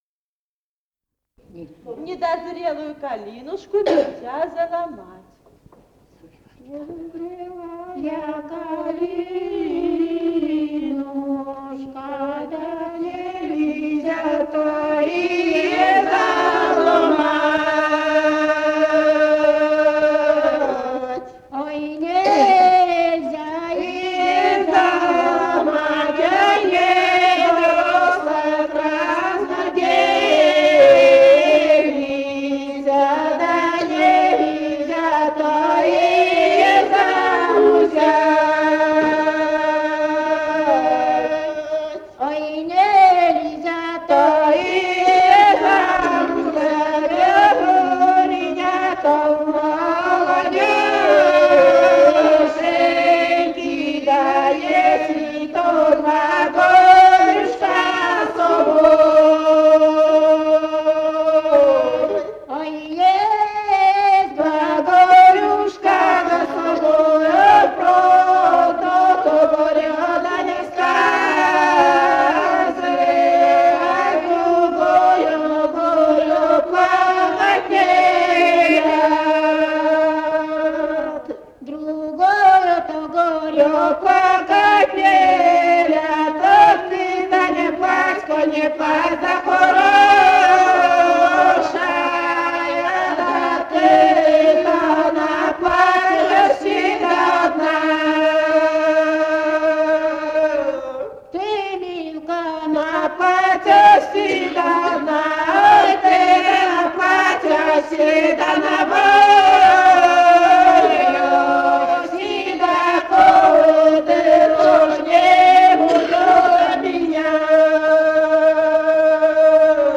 Живые голоса прошлого [[Описание файла::021. «Недозрелая калинушка» (лирическая в свадебном обряде).